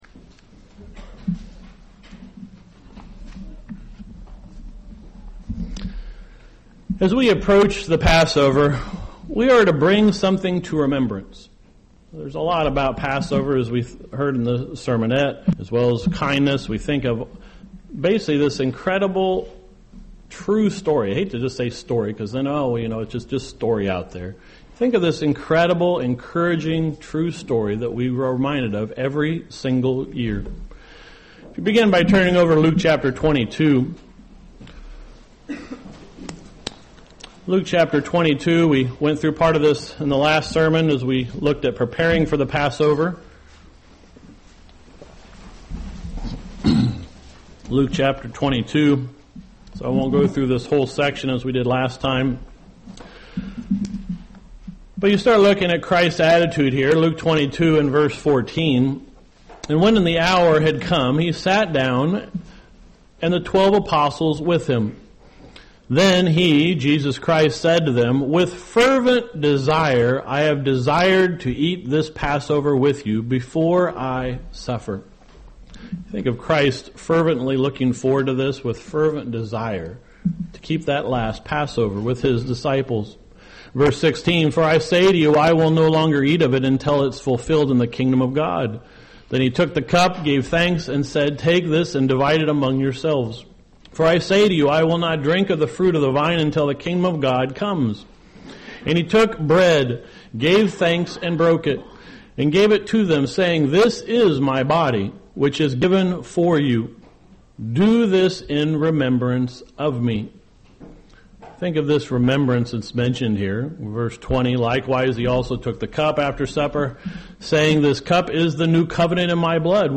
(March 13, 2026 update: The original audio file corrupted, so a copy of the same sermon given in Elkhart, IN is attached instead.)